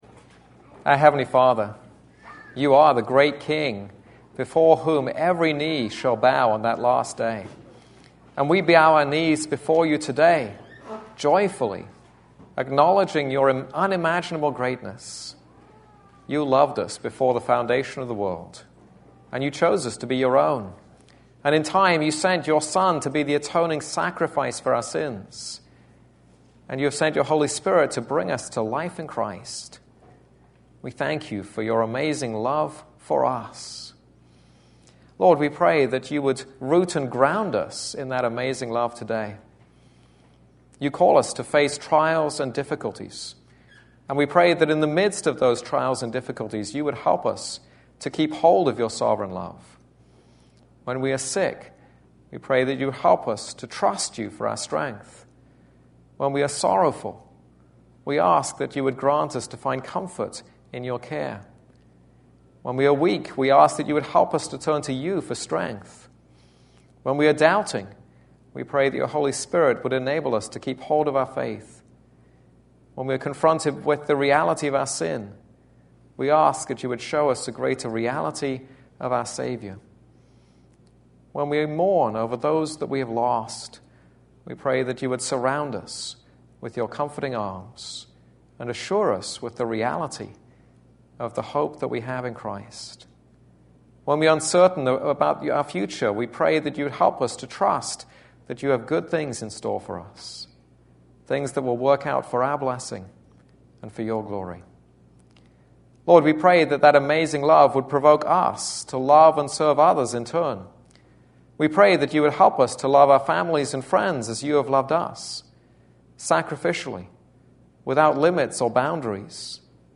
This is a sermon on Song of Songs 5:3-6:3.